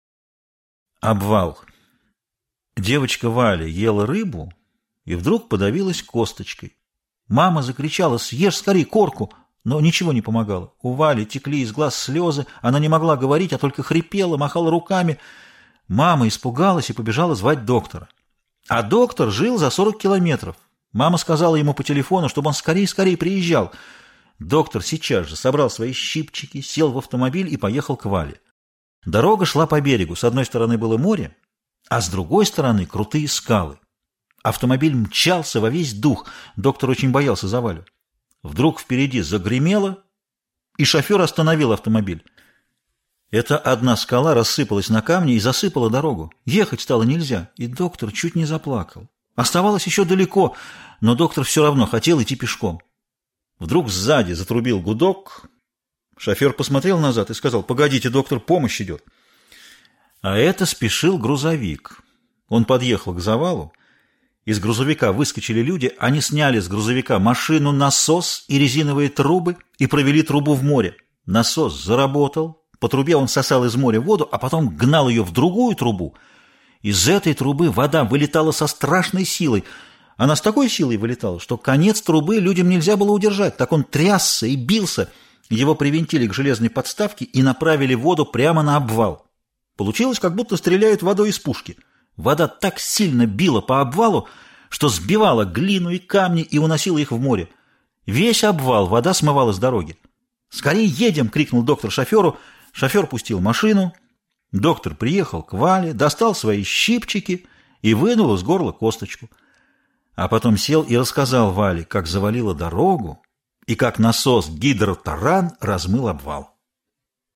Аудиорассказ «Обвал»